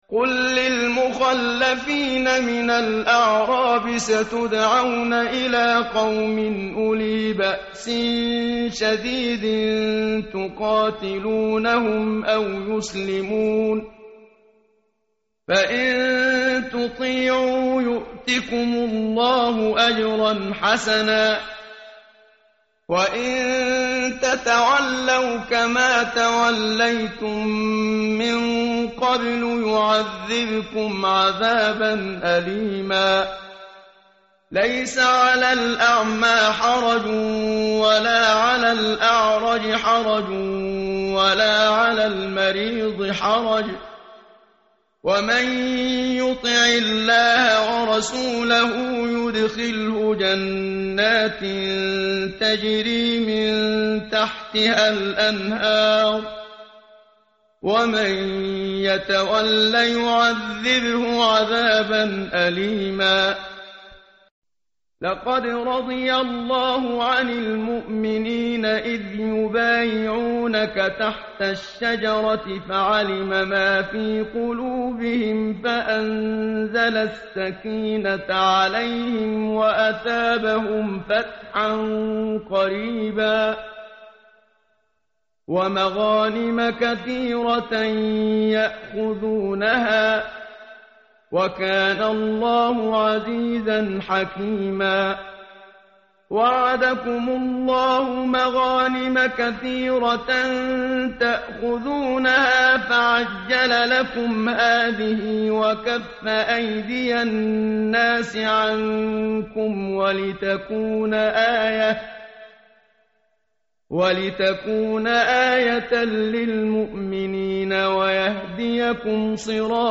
tartil_menshavi_page_513.mp3